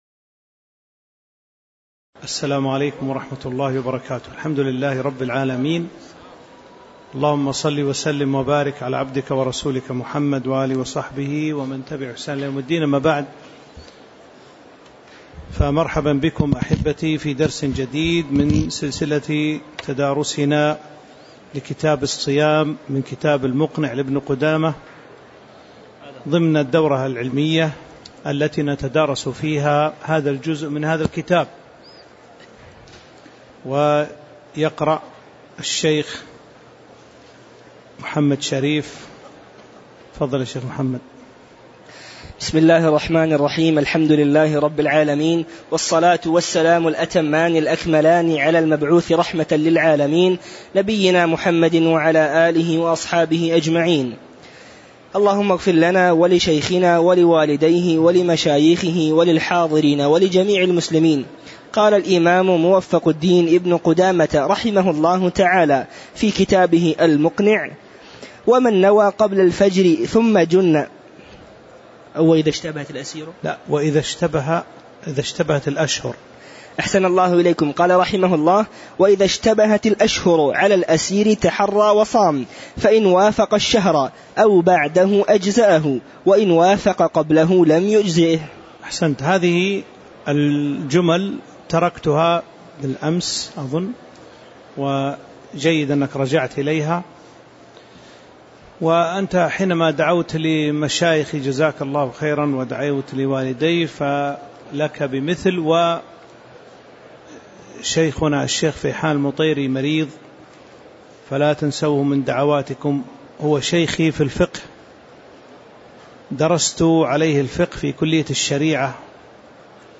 تاريخ النشر ٢٣ شعبان ١٤٤٥ هـ المكان: المسجد النبوي الشيخ